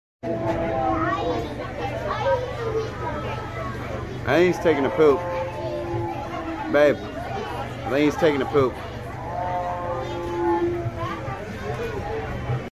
tiger taking a 💩 at sound effects free download
tiger taking a 💩 at st louis zoo